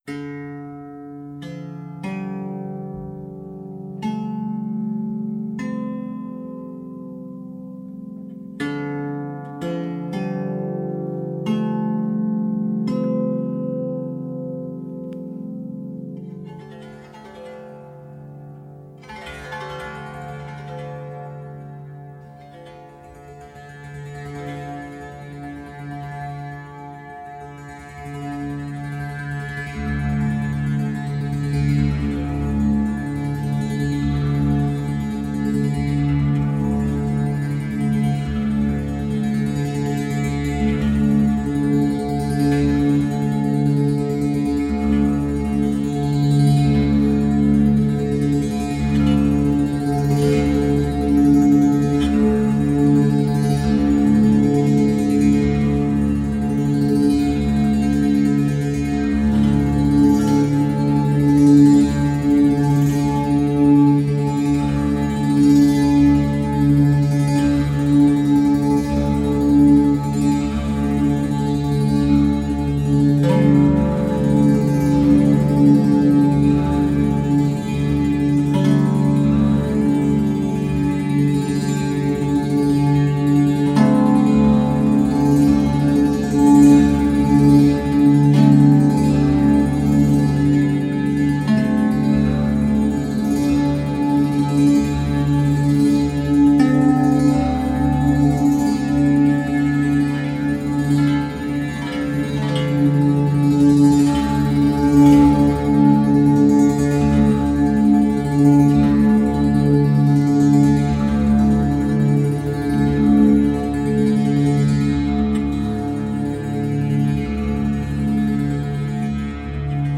• monolina monochord melody.wav
monolina_monochord_melody_k11.wav